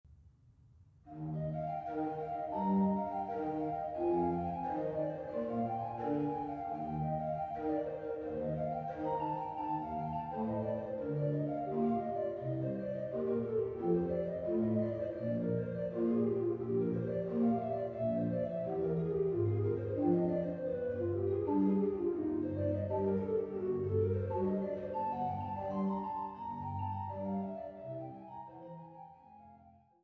gespielt an der Trost-Orgel der Schlosskirche Altenburg